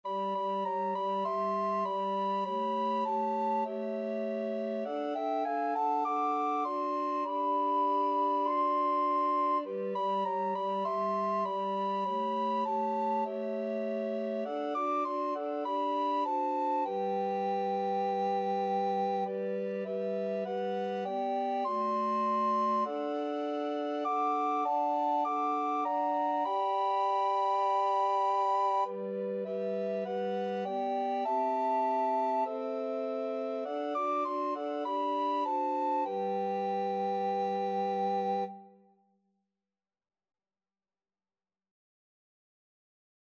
Soprano RecorderAlto RecorderTenor RecorderBass Recorder
4/4 (View more 4/4 Music)
Classical (View more Classical Recorder Quartet Music)